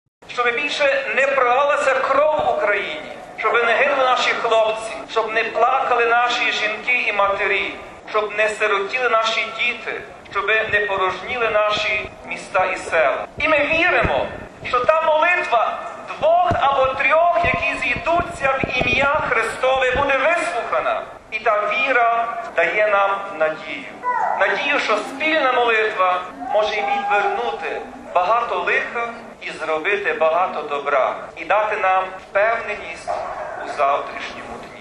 Звертаючись до прочан, Блаженніший Святослав сказав: RealAudio